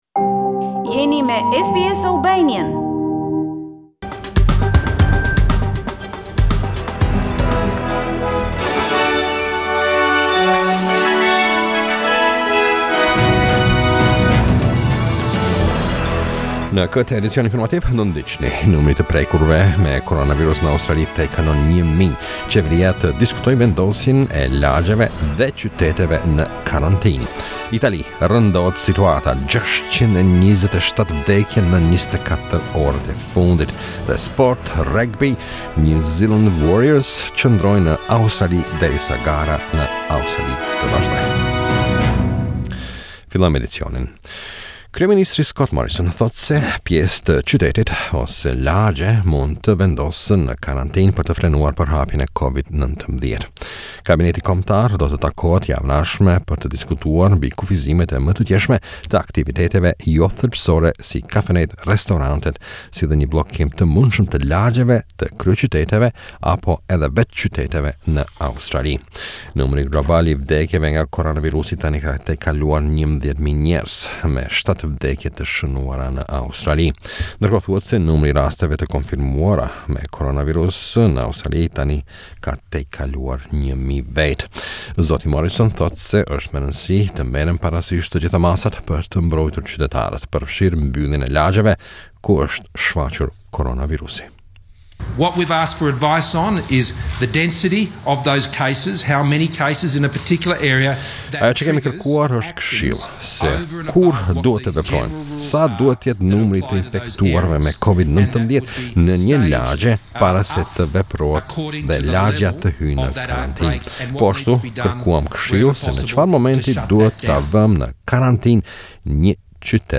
SBS News Bulletin 21 March 2020